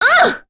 Miruru-Vox_Damage_01.wav